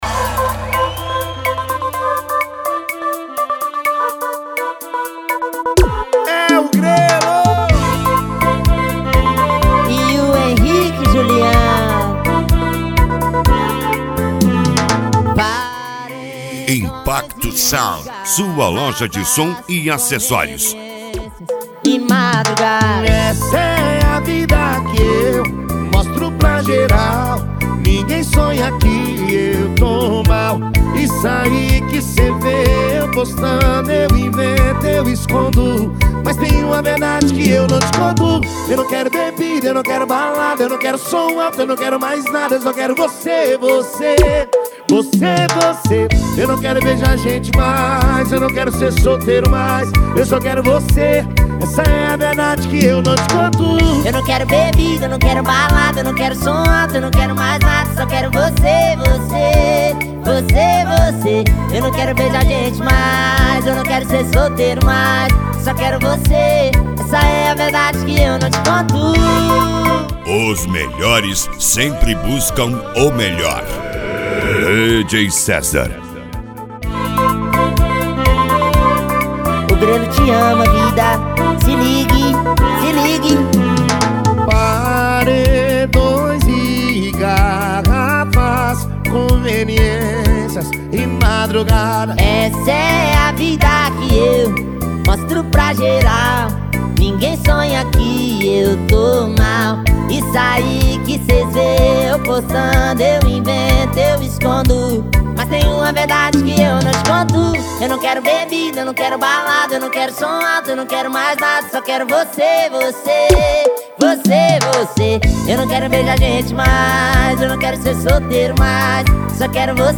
Modao
SERTANEJO
Sertanejo Raiz
Sertanejo Universitario